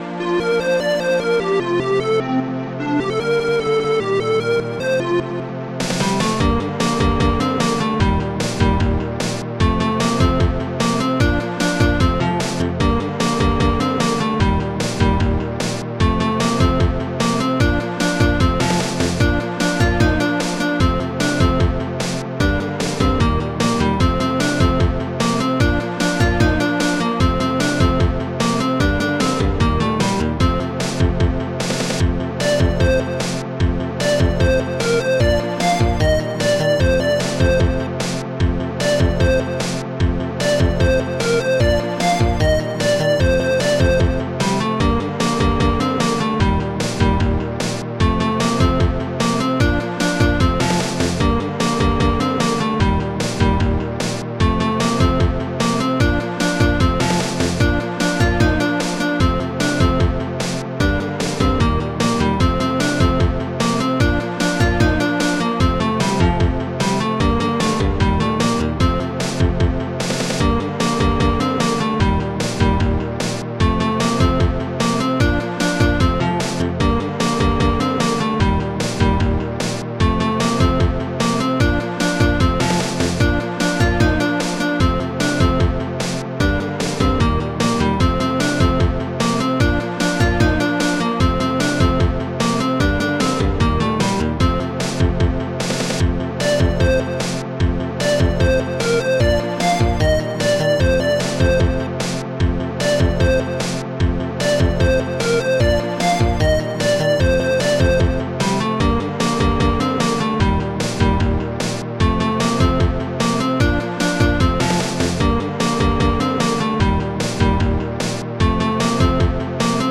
Protracker Module
Type Protracker and family